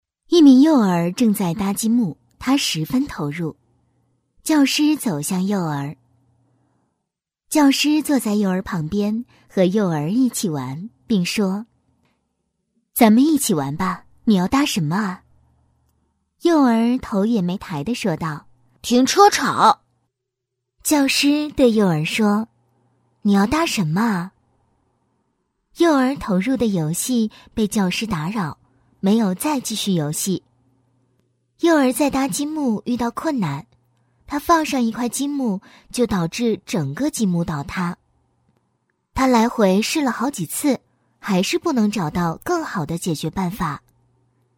女218-动画课 女声旁白
女218-温柔甜美 年轻稳重
女218-动画课 女声旁白.mp3